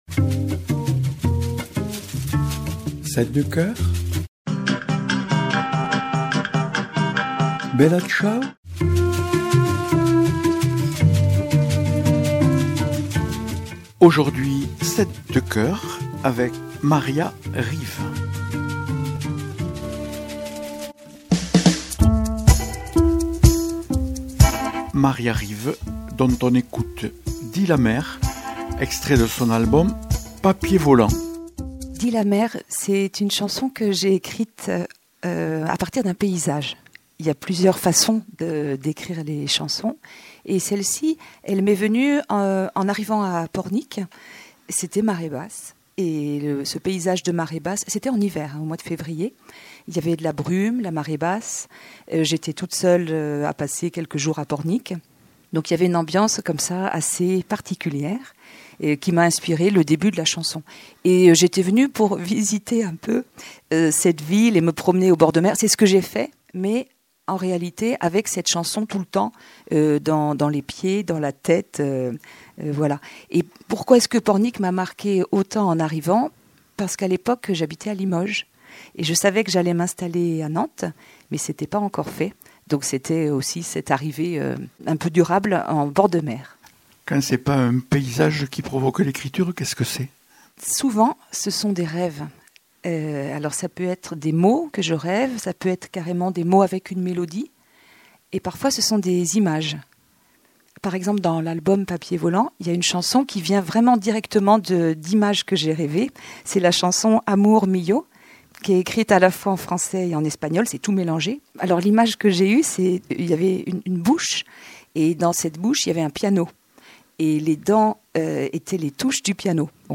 Émissions
chanteuse-musicienne et compositrice